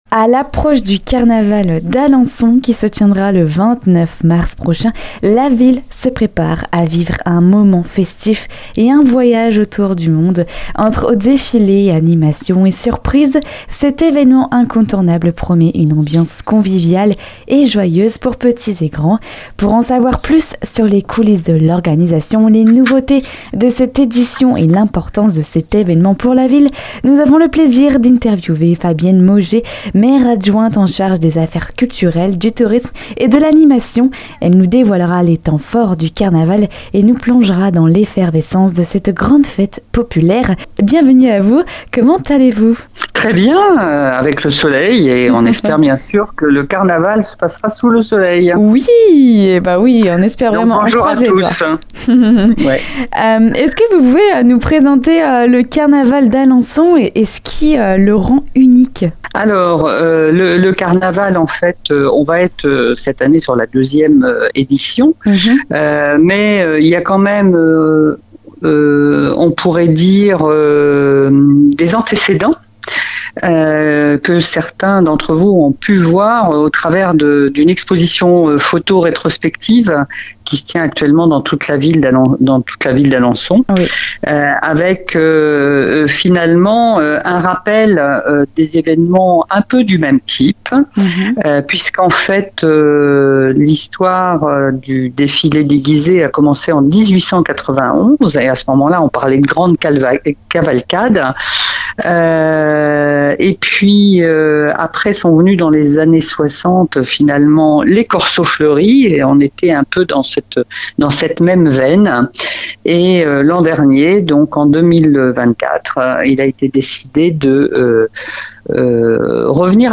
Nous avons le plaisir d'accueillir Fabienne Mauger, Maire-Adjointe en charge des affaires culturelles, du tourisme et de l’animation. Elle est avec nous pour discuter du Carnaval d'Alençon.